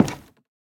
Minecraft Version Minecraft Version 1.21.5 Latest Release | Latest Snapshot 1.21.5 / assets / minecraft / sounds / block / cherrywood_door / toggle1.ogg Compare With Compare With Latest Release | Latest Snapshot